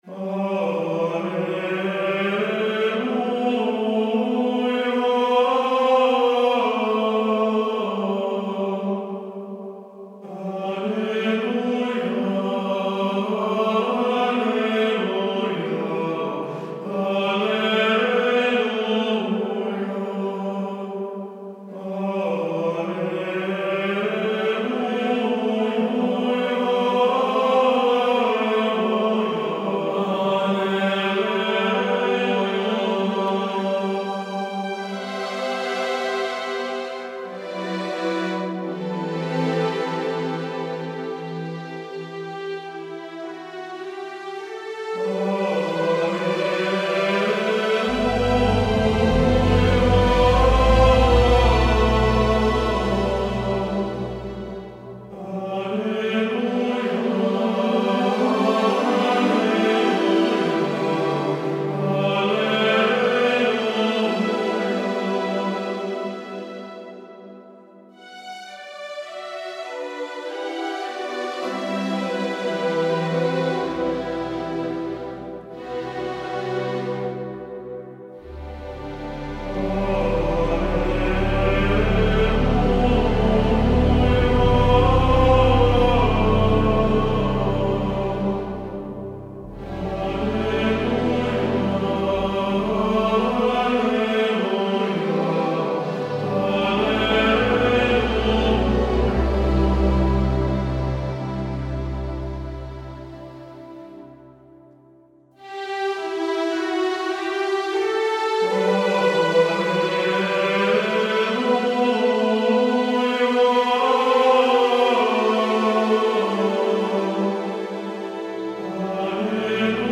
中世纪崇拜
• 源于9世纪欧洲的格里高利拉丁语圣歌之声